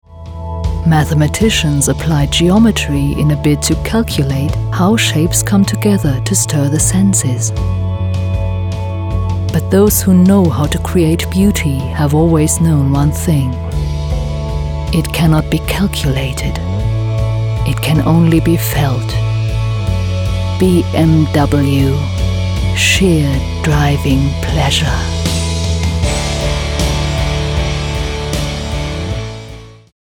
Female
Approachable, Assured, Authoritative, Bright, Character, Confident, Conversational, Cool, Corporate, Deep, Engaging, Friendly, Gravitas, Natural, Reassuring, Sarcastic, Smooth, Soft, Warm, Witty, Versatile
German (native), English with slight European accent
Voice reels
Microphone: Neumann TLM 103